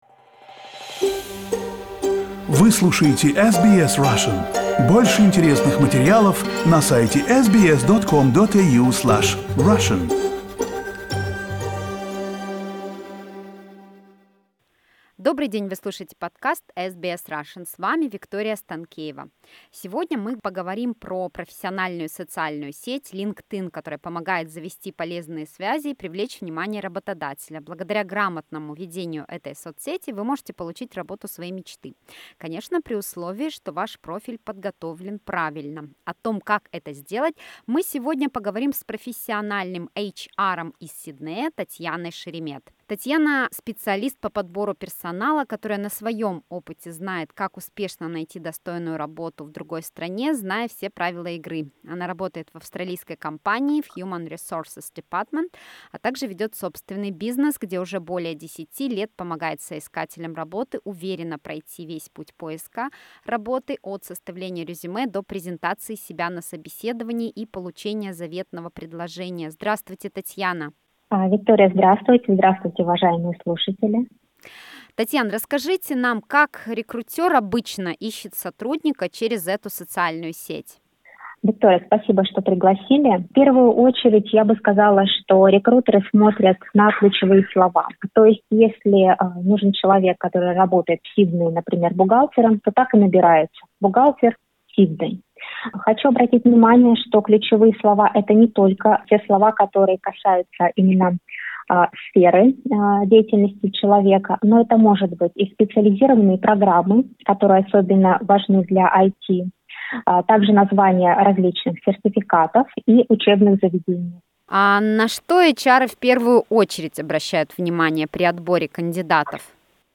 В интервью